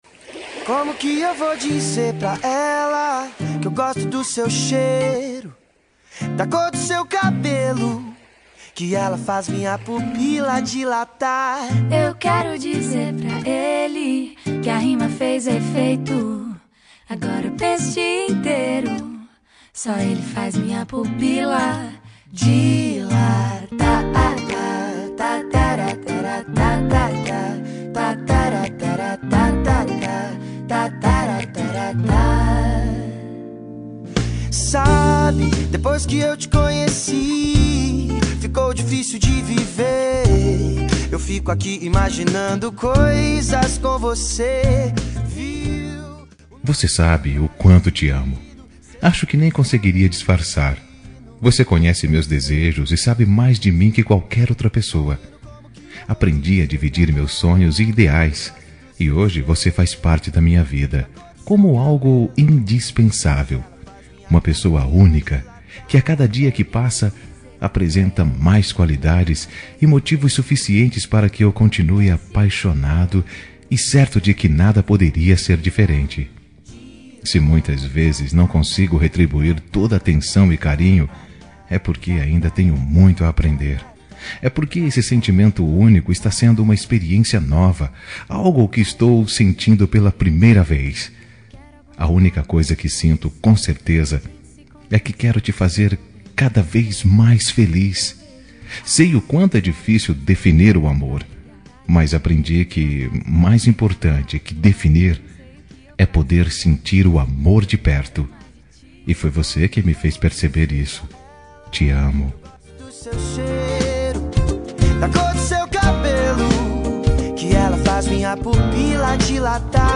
Telemensagem Romântica GLS – Voz Masculina – Cód: 5487